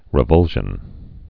(rĭ-vŭlshən)